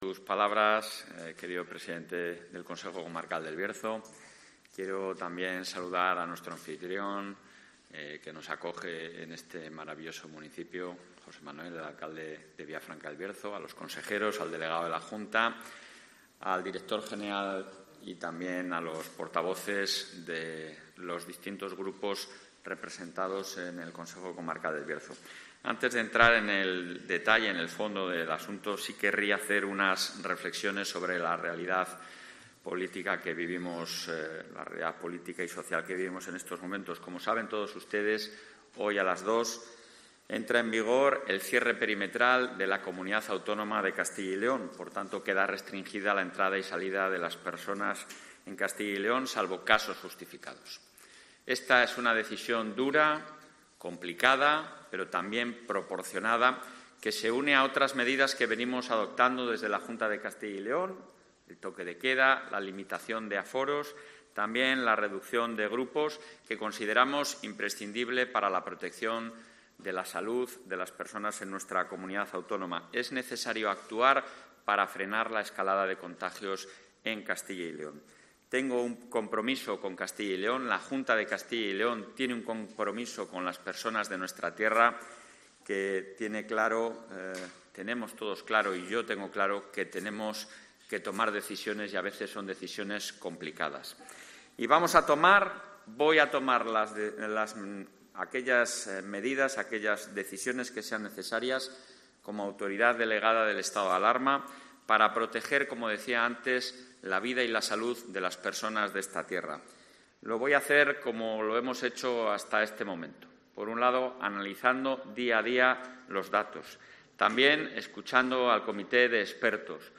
Escucha aquí al presidente de la Junta de Castilla y León, Alfonso Fernández Mañueco, y al presidente del Consejo Comarcal del Bierzo, Gerardo Álvarez Courel